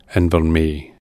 Invermay (/ˌɪnvərˈm/